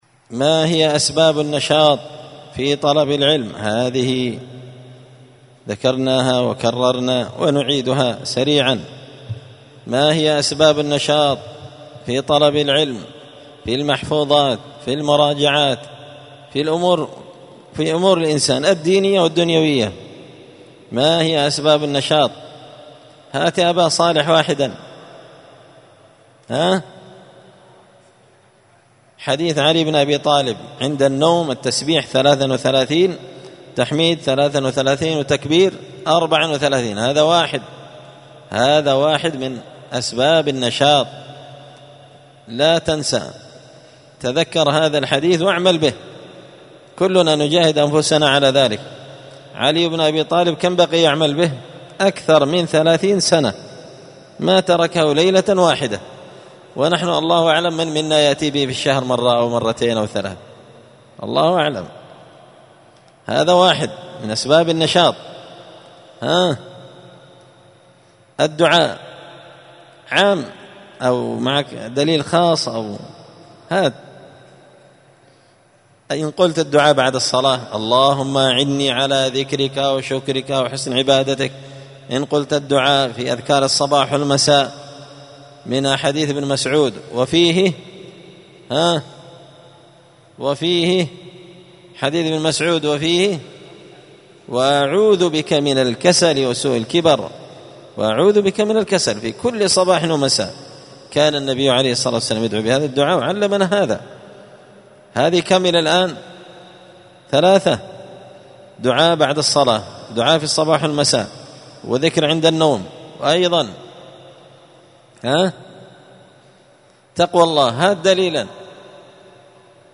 *أسباب النشاط في طلب العلم*نصائح ومقتطفات
دار الحديث بمسجد الفرقان ـ قشن ـ المهرة ـ اليمن